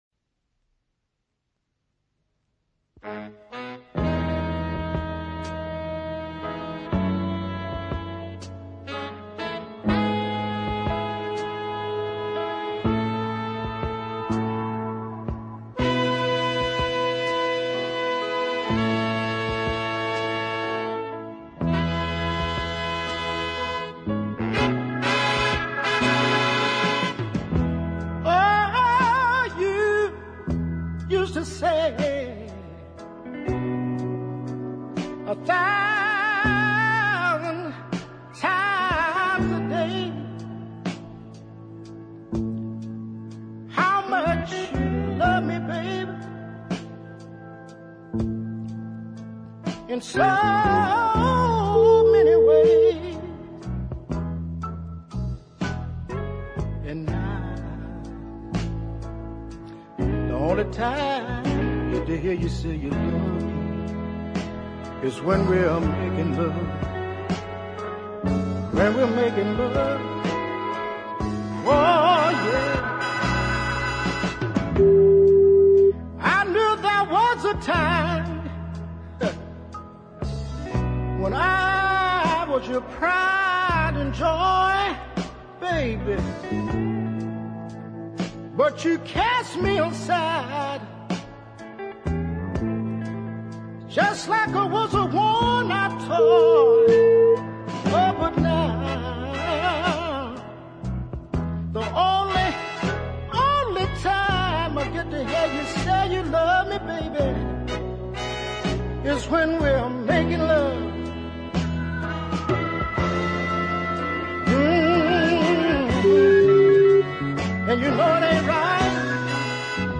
hard-hitting, indeed desperate, ballad